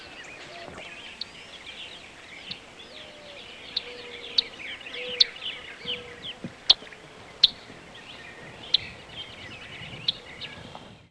Red-winged Blackbird diurnal flight calls
Male in flight giving "teek" call. Mourning Dove and Northern Mockingbird in the background.